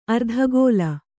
♪ ardhagōla